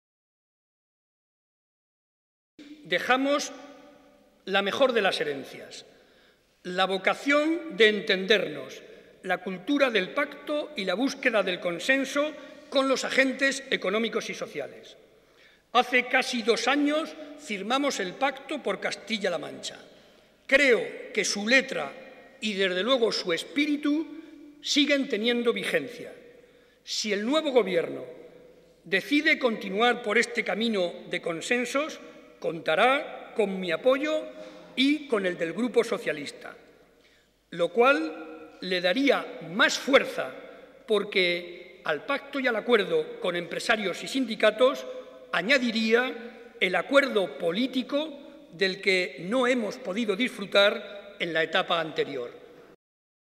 Cortes de audio de la rueda de prensa
audio_Barreda_Discurso_Debate_Investidura_210611_5